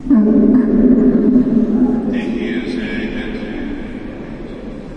教堂里的声音" 神圣的屁
描述：罗马天主教会是仪式当执事说“耶和华与你同在”时，在宣讲福音之前，人们会回应，“和你的灵”。
Tag: 罗马天主教 休息 - 风 声音 大教堂 荷兰 priester 牧师 scheet 放屁 windje 明确 气体 脱希尔 - zij-MET-U